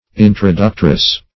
Introductress \In`tro*duc"tress\, n.